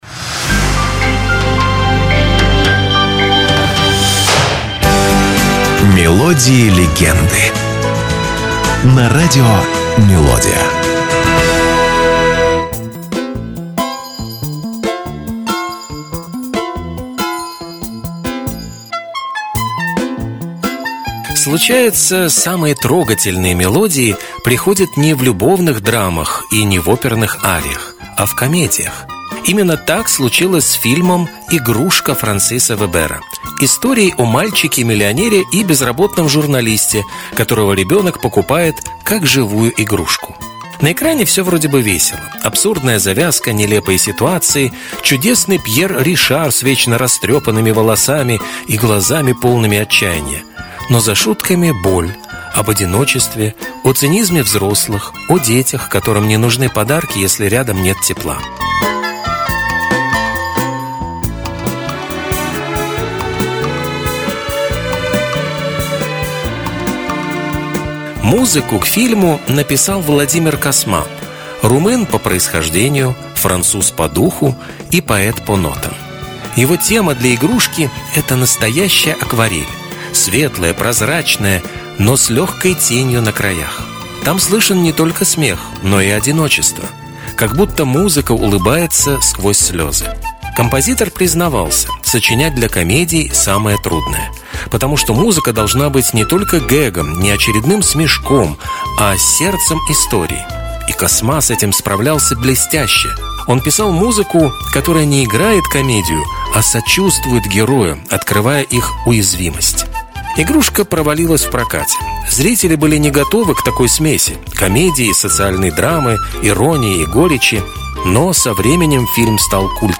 Это короткие музыкальные рассказы с душевным настроением, атмосферой ностальгии и лёгкой интригой. Вы услышите песни, которые звучали десятилетиями и, возможно, впервые узнаете, что стоит за этими знакомыми нотами.